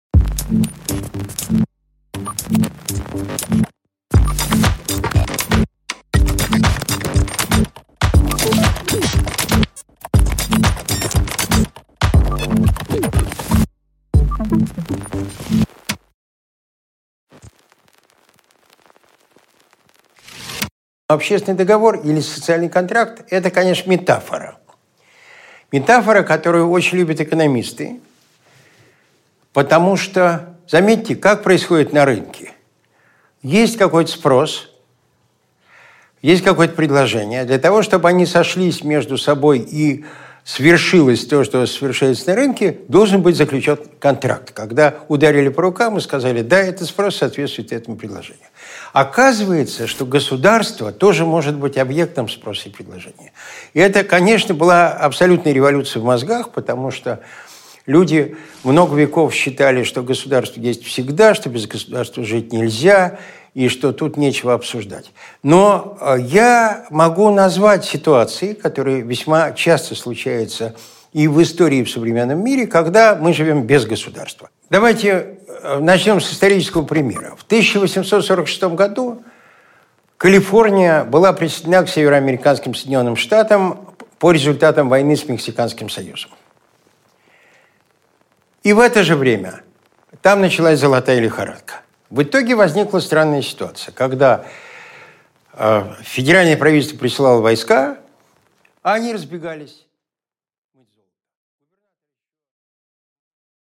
Аудиокнига Кто с кем договорился? | Библиотека аудиокниг